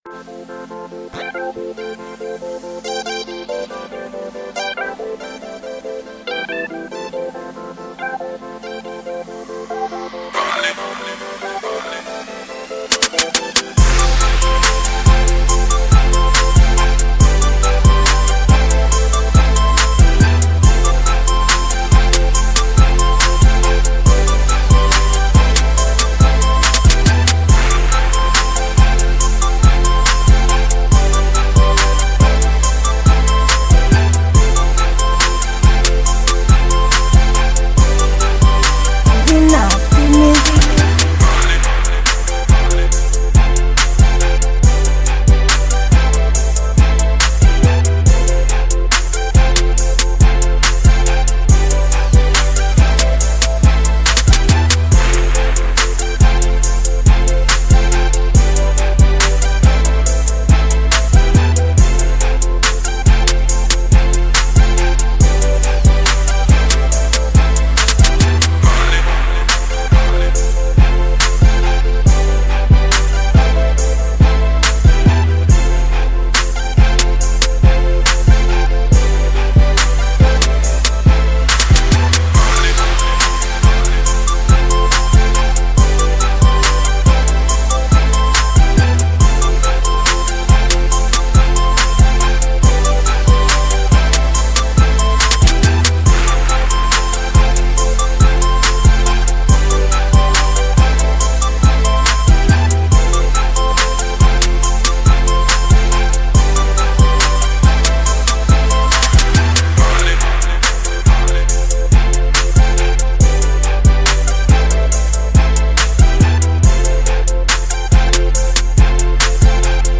Genre: Beat.